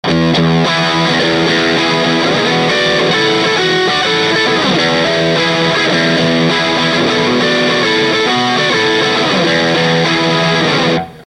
で、「赤」と「青」共に、つまみフルでの音比較です。
Guitar Fender STRTOCASTER
Amplifier VOX AD30VT AC30TB
GAIN全開VOLUME全開
回路は同じ筈なのに、こうも音が違うとは・・・